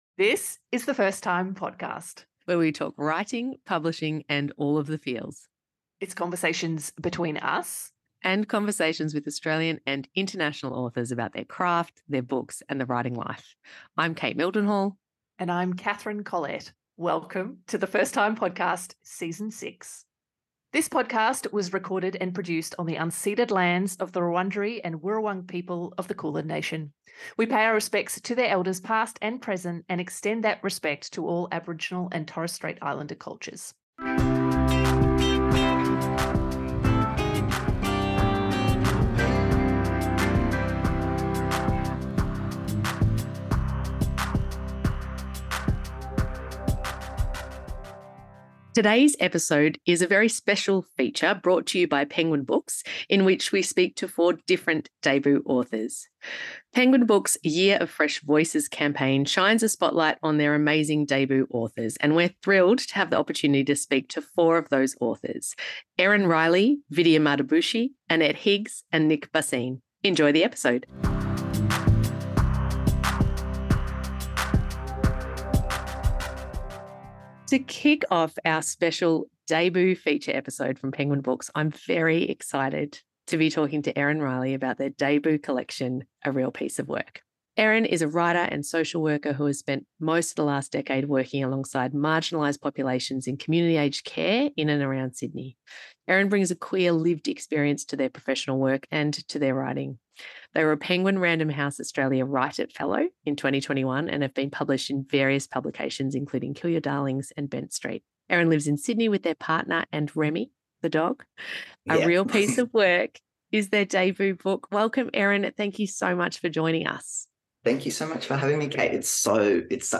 In this special Feature Episode brought to you by Penguin Books Australia, we speak to four debut authors about their new books.